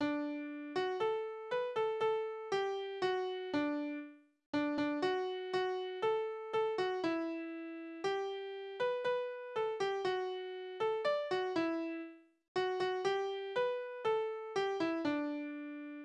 Liebeslieder: Bitte des Geliebten
Tonart: D-Dur
Taktart: 2/4
Tonumfang: Oktave
Besetzung: vokal